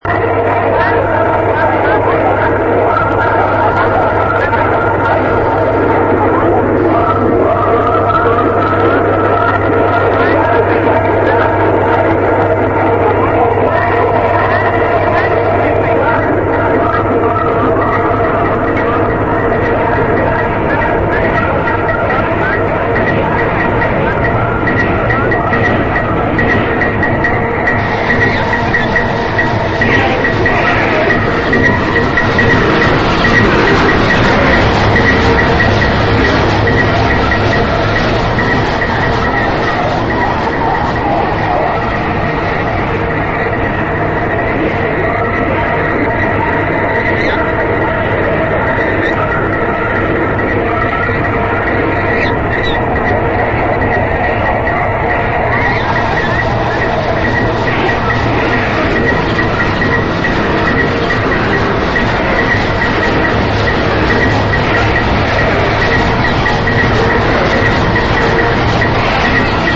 compilation of 30 Austrian composers on 4 CDs